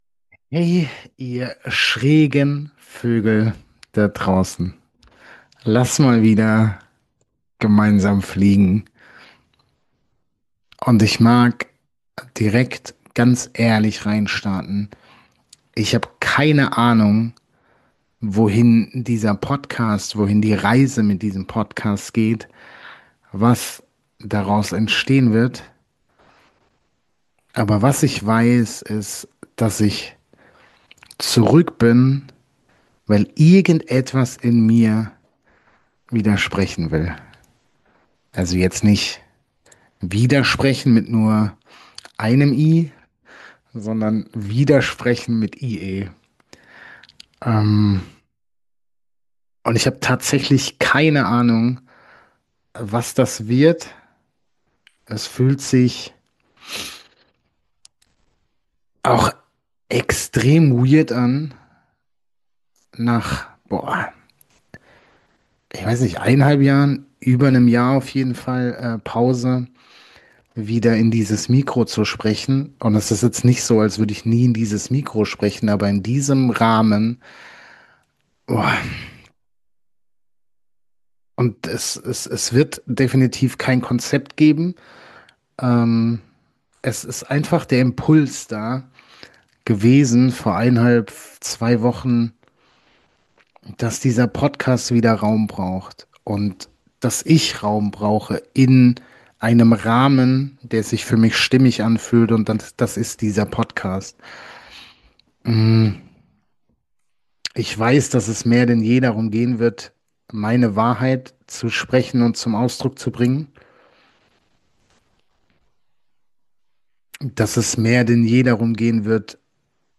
Ich tauche ein in Themen wie Geben ohne Erwartung, Einssein statt Trennung, Akzeptanz statt Widerstand und die Frage, was passiert, wenn wir aufhören, unser Sein zu optimieren und stattdessen einfach sind. Wenn Du spüren willst, wie es klingt, wenn der Schräge Vogel wieder anfängt zu fliegen - roh, unsortiert und echt - dann ist diese Episode für Dich.